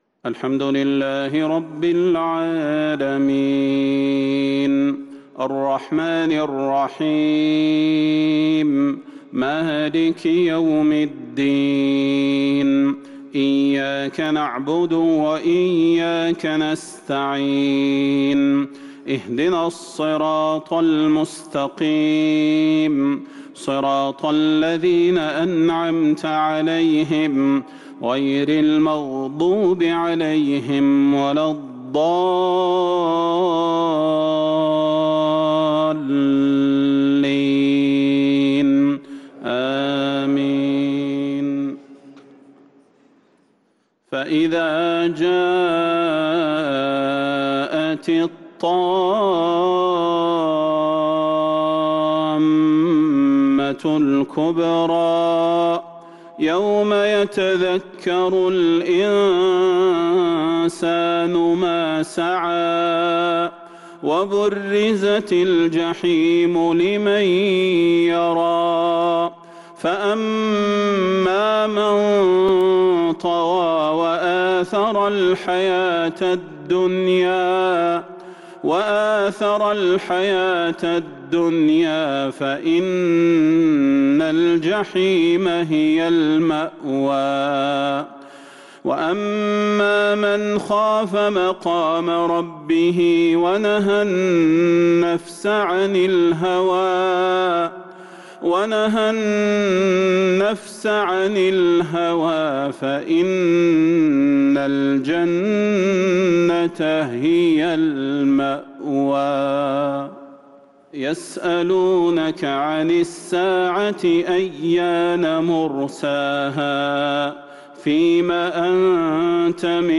عشاء الأحد 2-4-1443هـ من سورة النازعات | Isha prayer from surat An-Naziat 7/11/2021 > 1443 🕌 > الفروض - تلاوات الحرمين